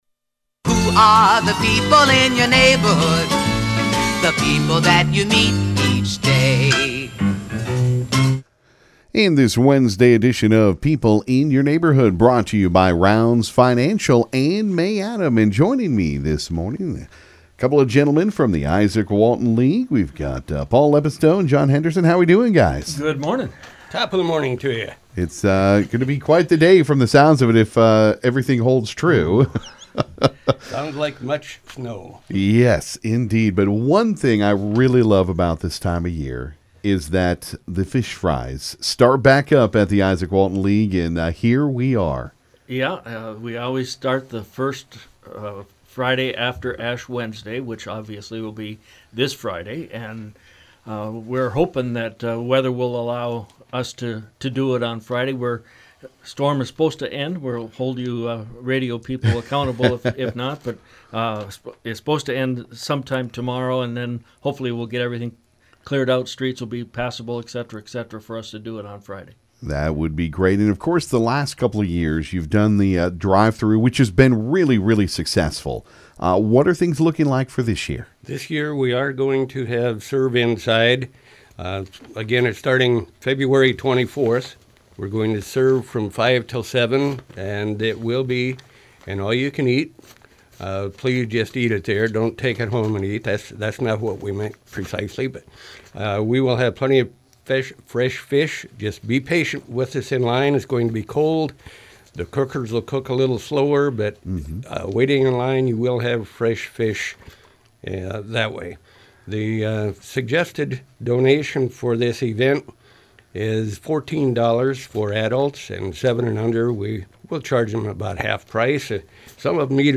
This morning during People In Your Neighborhood on KGFX we talked Fish Fry’s.